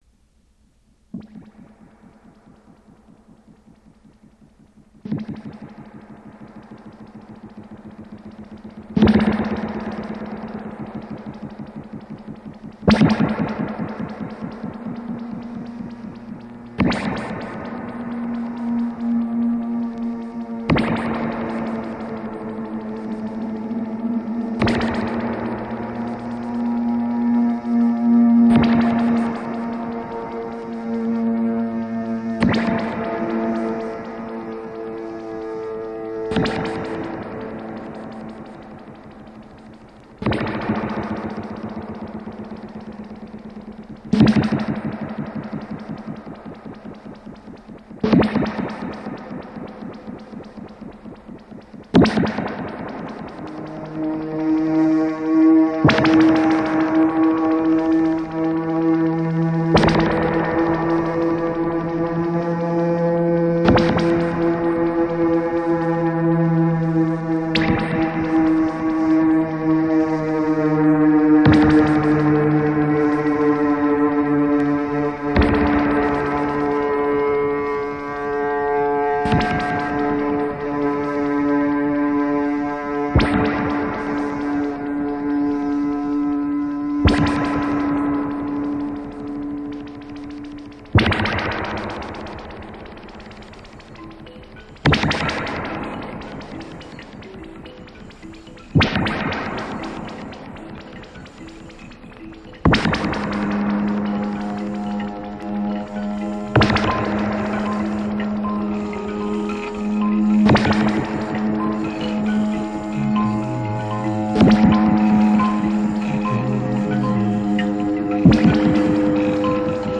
Avant-Garde Psych